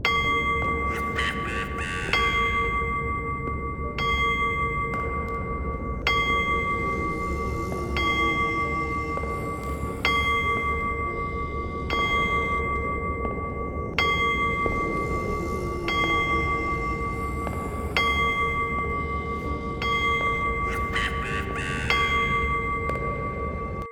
cuckoo-clock-12.wav